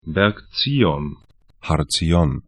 Aussprache
Berg Zion bɛrk 'tsi:ɔn Har Ziyyon har tsi'jɔn he Berg / mountain 31°46'N, 35°13'E